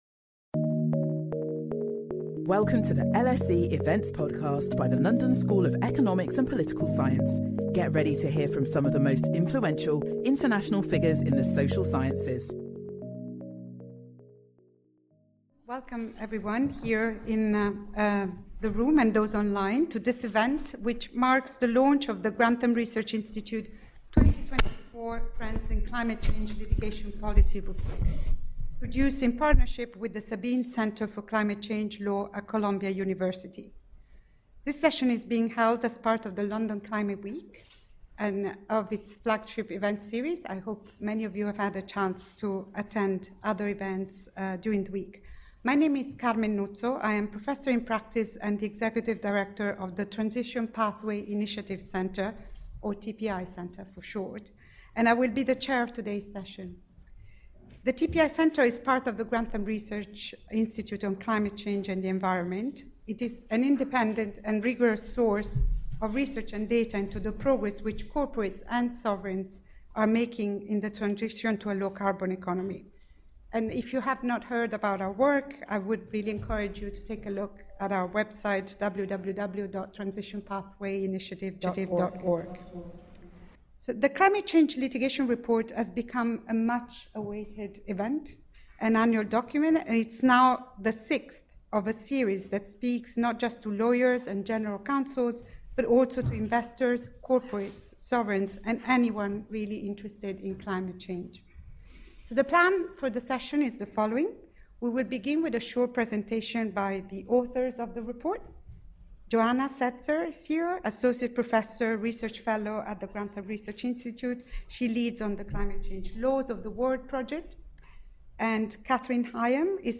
The report's authors share their insights and discuss with experts on the latest trends in climate change litigation.